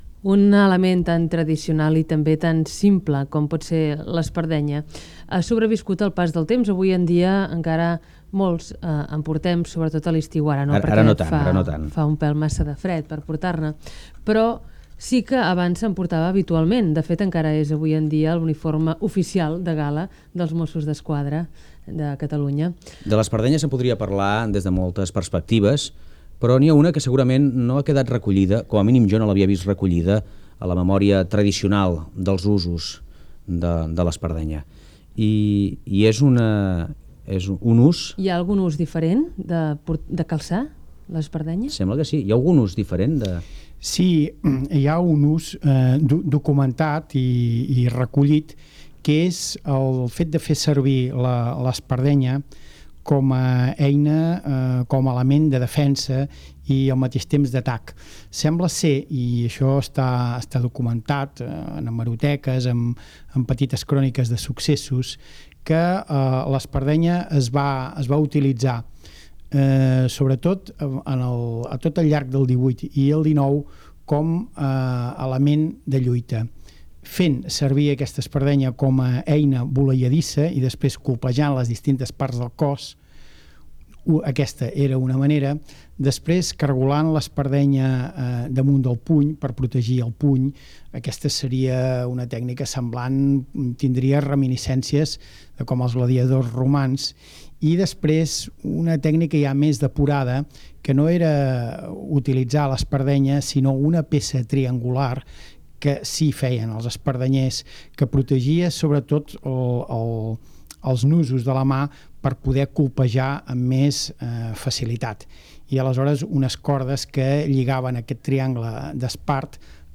Entrevista a un expert sobre l'espardenya i, en concret, a l'ús que se'n feia en el passat com a defensa i atac.
Fragment extret de diferents cintes trobades a Catalunya Ràdio